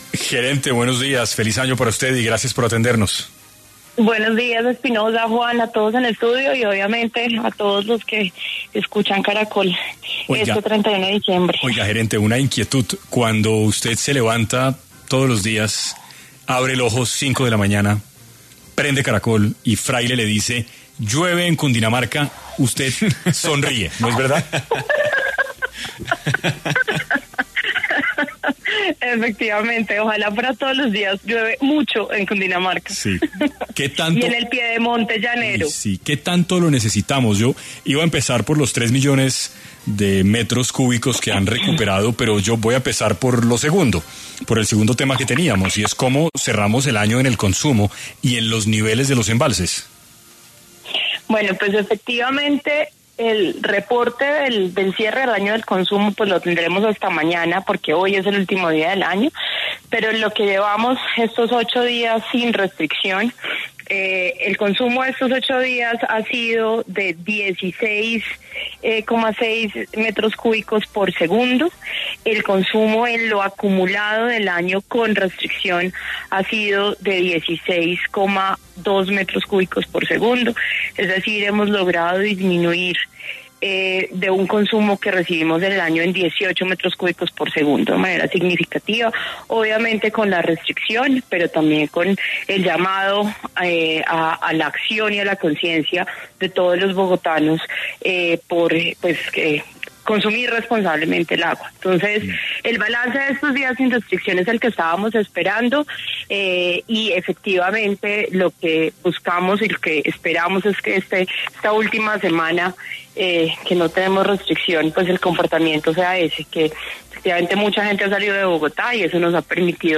En 6AM de Caracol Radio estuvo Natasha Avendaño, gerente de la Empresa de Acueducto y Alcantarillado de Bogotá, para hablar sobre cómo se cierra el año en cuestiones de consumo y niveles de los embalses.